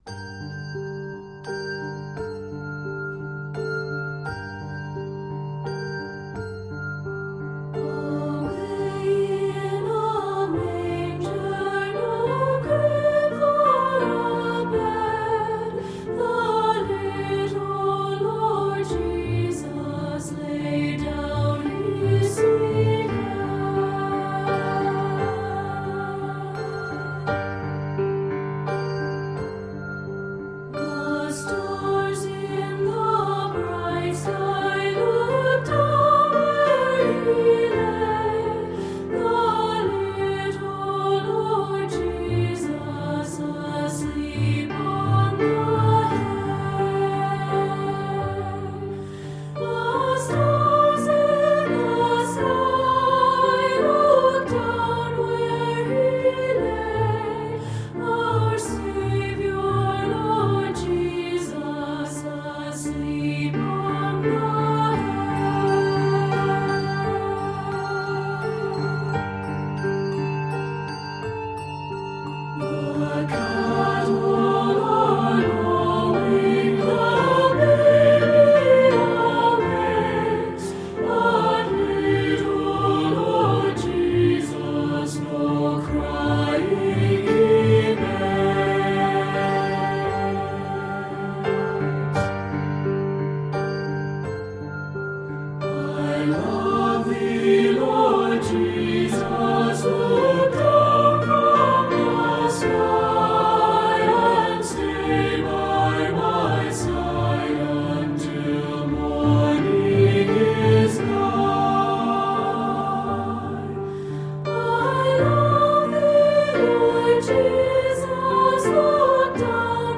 The hauntingly beautiful English folk tune